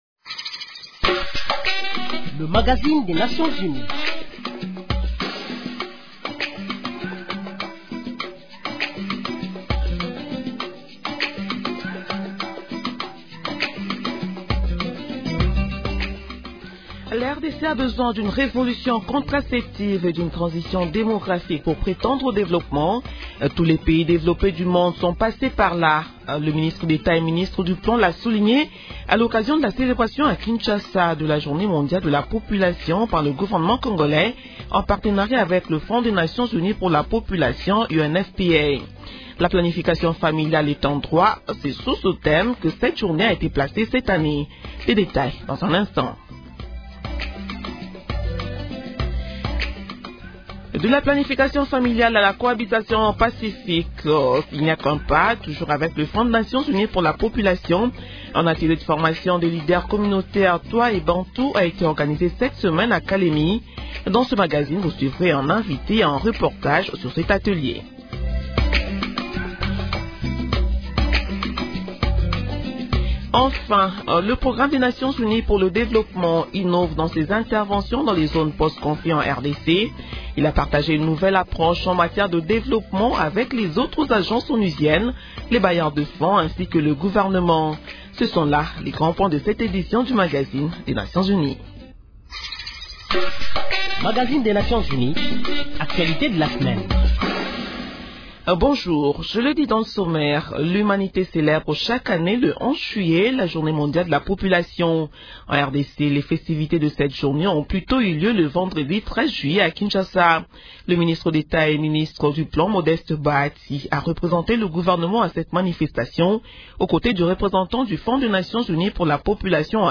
Brèves *Ouvrons cette page par une nouvelle du HCR au Nord-Ubangi. Poursuite de l’opération de remise d’attestations aux réfugiés centrafricains de la vague de mai à juillet 2017.